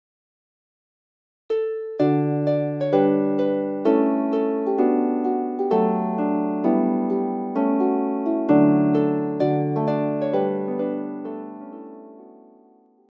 arranged for solo lever and pedal harp